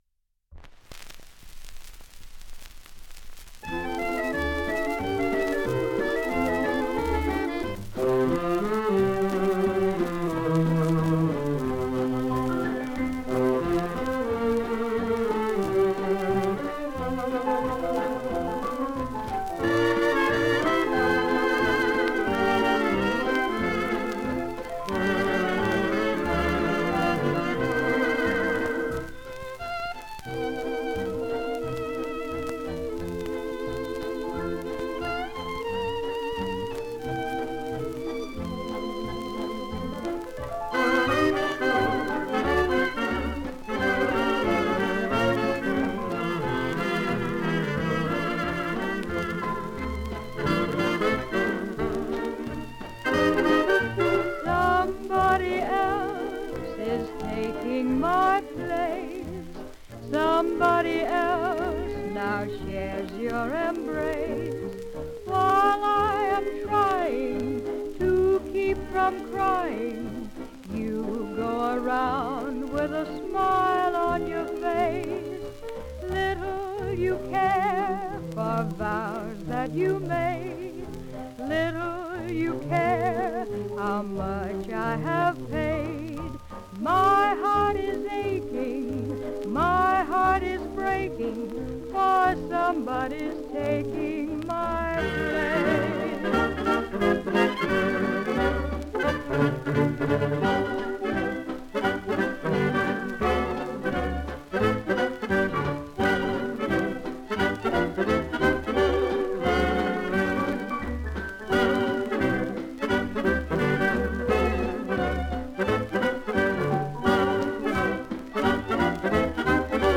Genre: Popular Music.